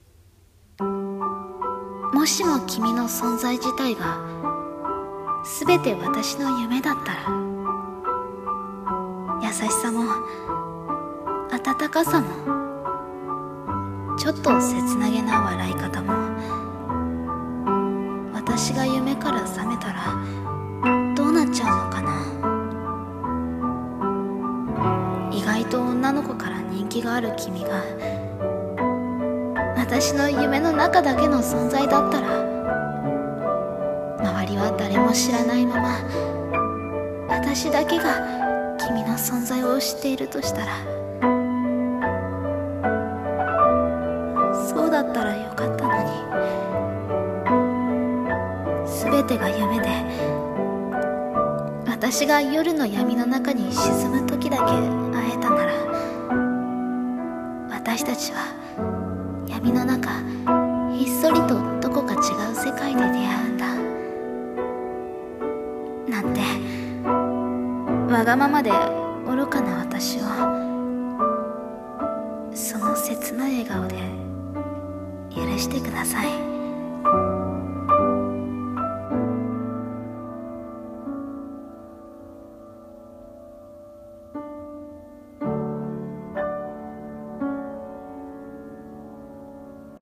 【朗読声劇】